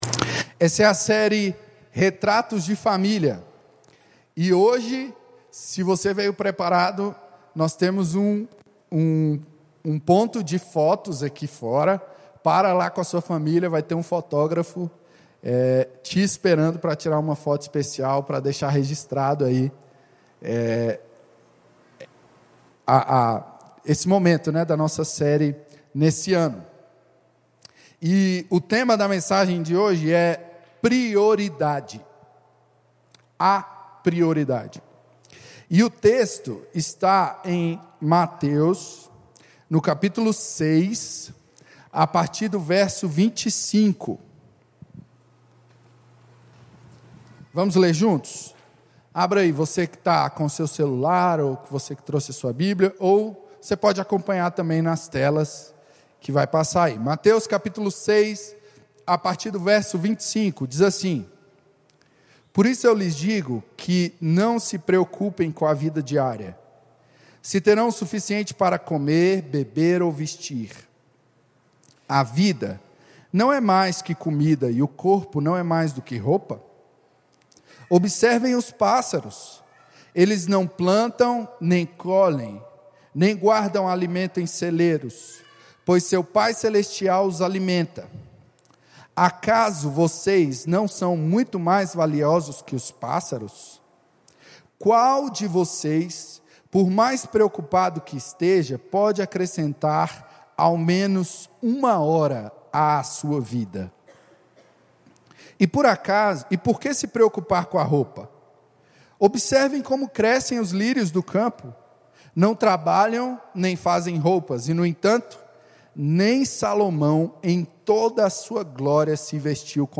Mensagem apresentada por Igreja Batista Capital como parte da série Retratos de Família 2021 na Igreja Batista Capital.